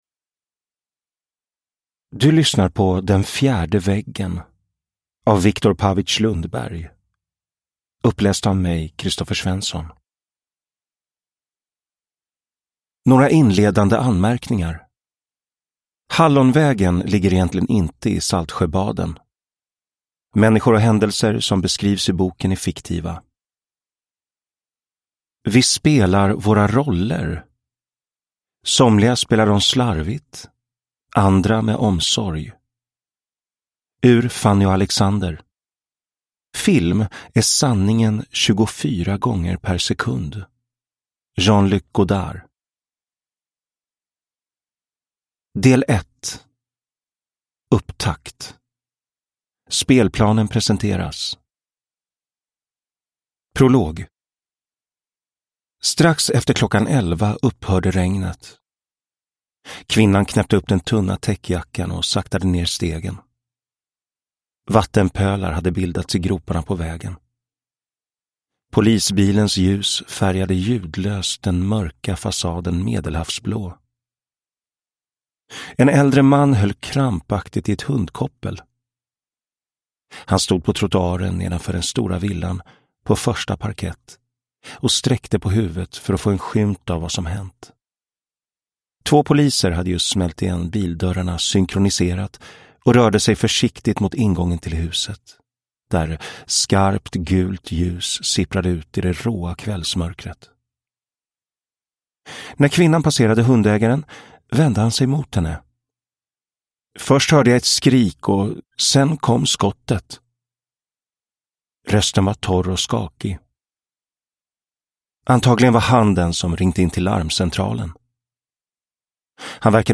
Den fjärde väggen – Ljudbok